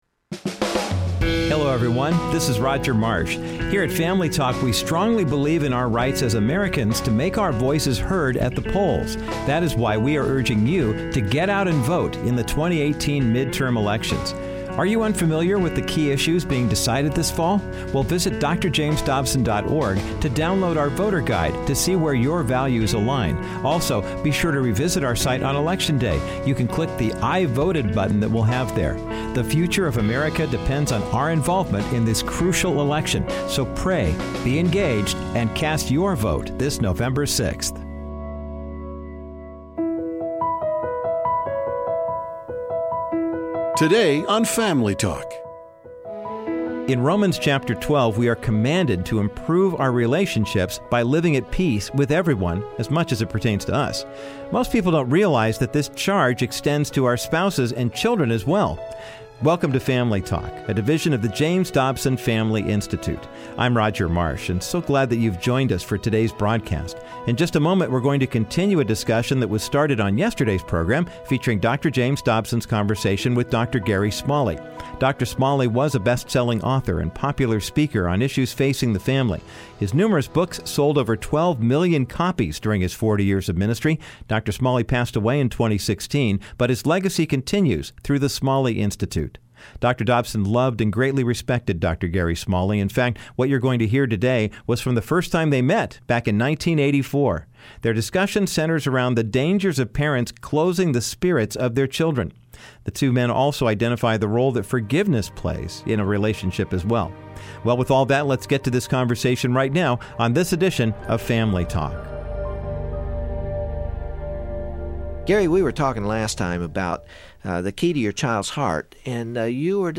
Dr. Dobson concludes his interview with the author of The Key to Your Childs Heart, Dr. Gary Smalley. They continue addressing how our words and actions can negatively affect our spouse, and the importance of forgiveness in all relationships.